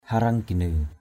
/ha-rʌŋ-ɡ͡ɣa-nɯ/ (cv.) areng-gana ar$gn% [Cam M] areng-ginâ (cn.) mala-ikat mlikT [A, 382] /mə-la-i-ka:t/ (d.) thiên thần; tiên nữ = esprits célestes. angel.
hareng-ginax.mp3